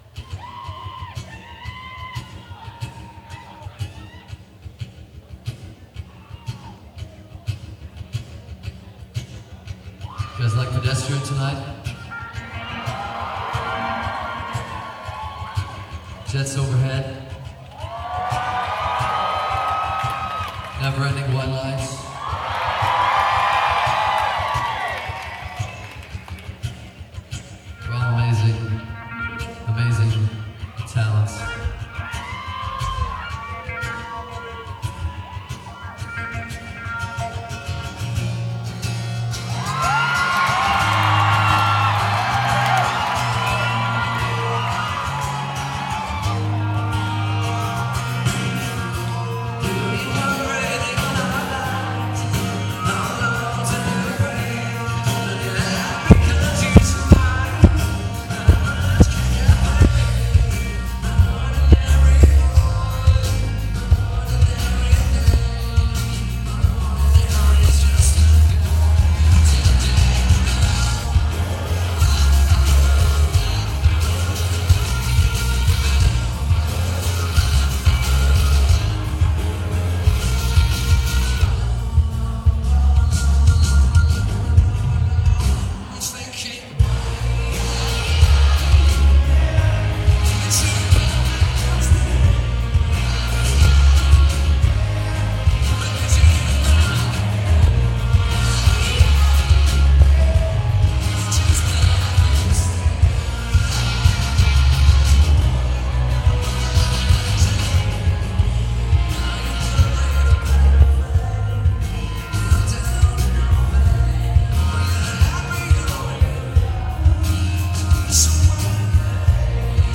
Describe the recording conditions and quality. Remember, these are audience recordings.